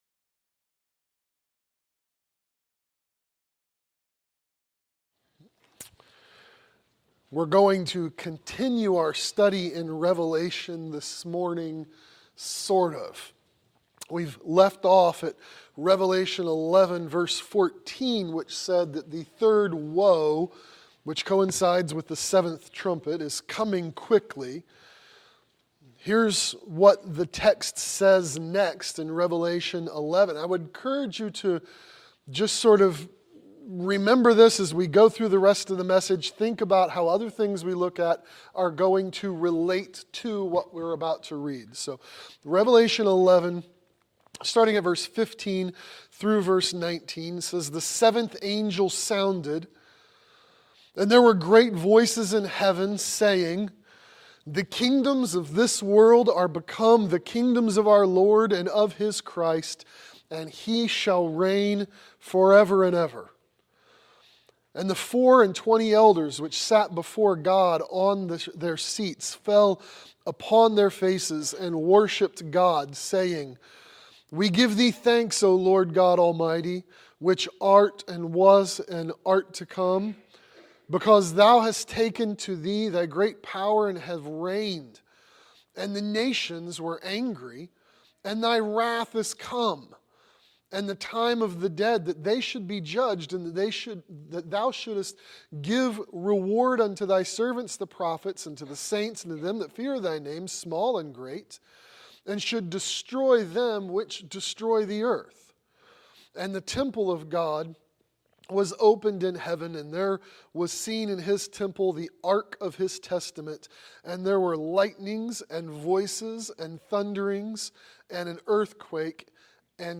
Post-Tribulation Rapture | SermonAudio Broadcaster is Live View the Live Stream Share this sermon Disabled by adblocker Copy URL Copied!